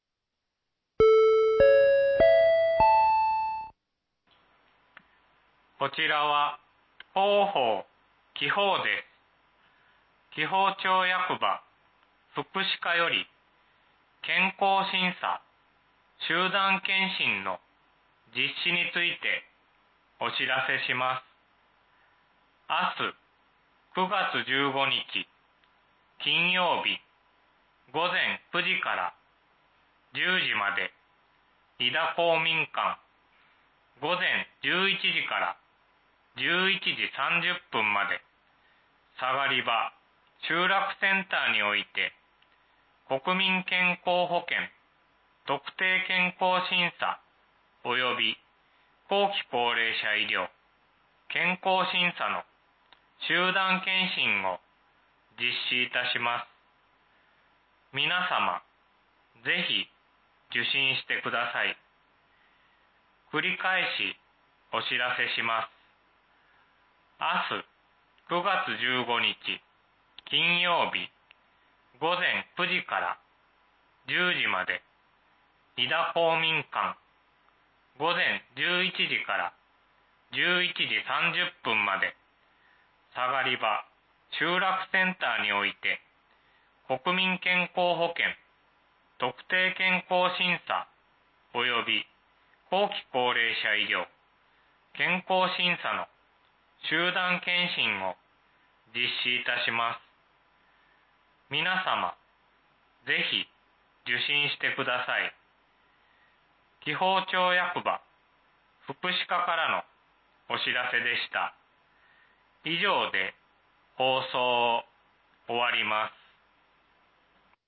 放送音声